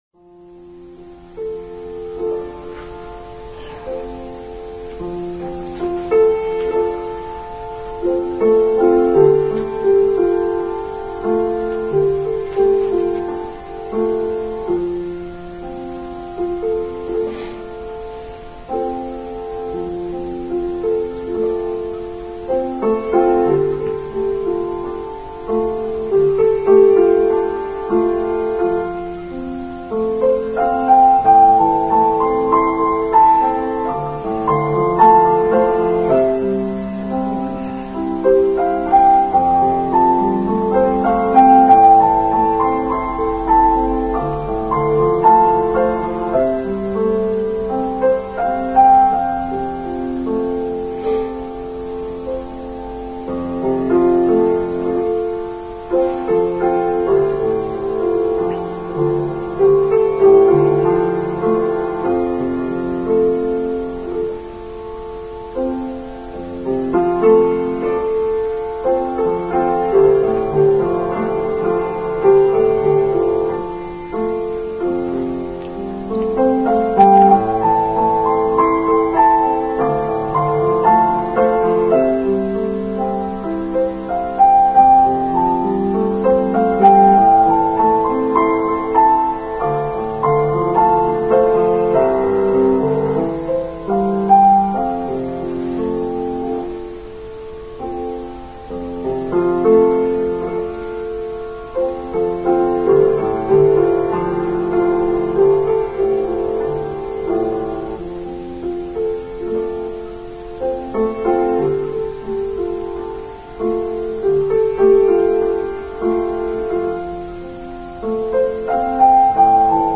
Return of the Light: Online Service for 27th December 2020